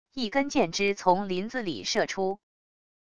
一根箭支从林子里射出wav音频